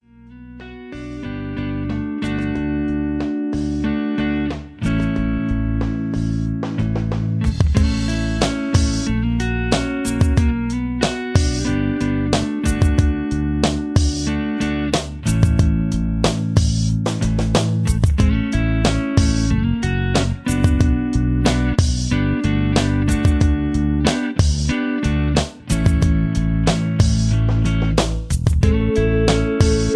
mp3 backing tracks
rock and roll